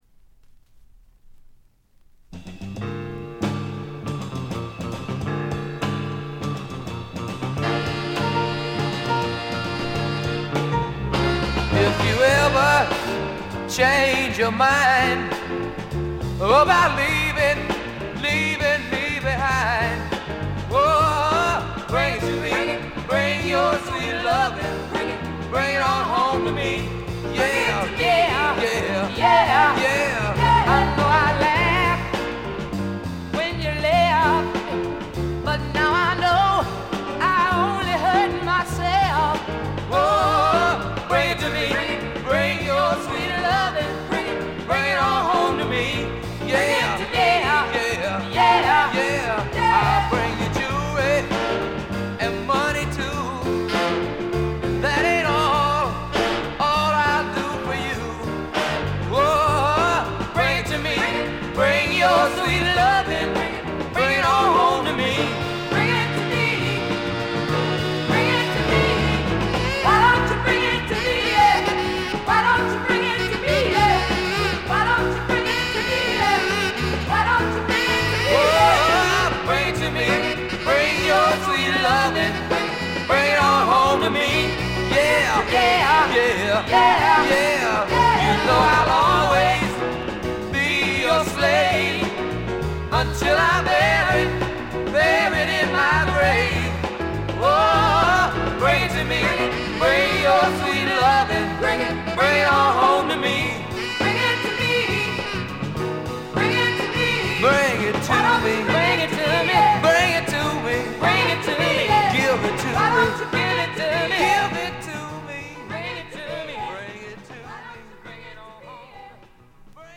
ほとんどノイズ感無し。
試聴曲は現品からの取り込み音源です。